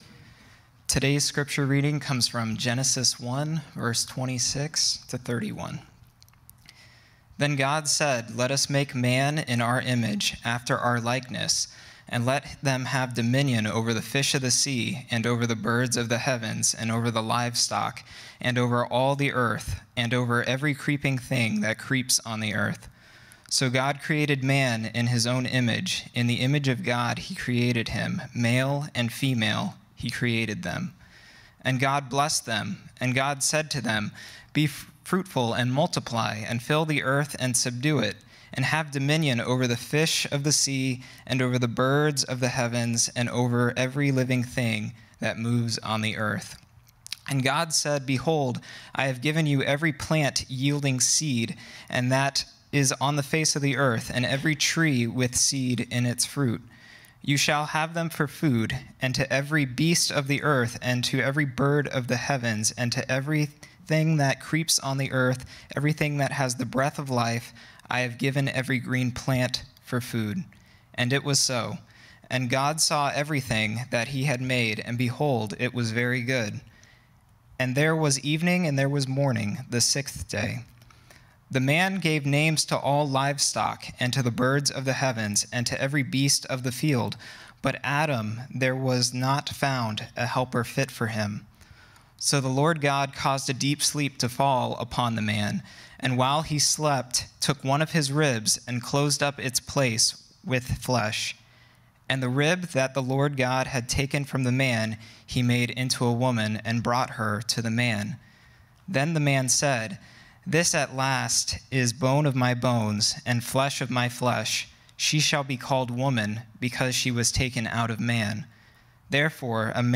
sermon-audio-9.19.21.m4a